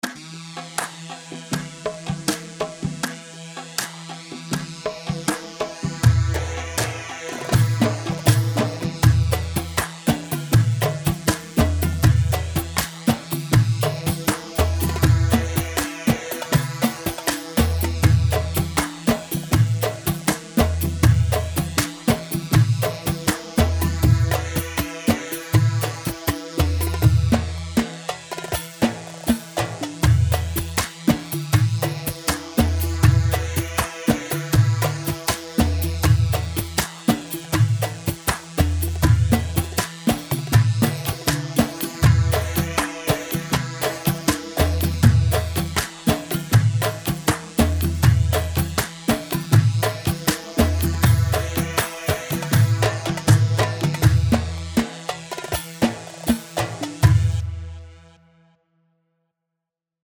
Hewa Modern 4/4 80 هيوا
Hewa-Modern-80.mp3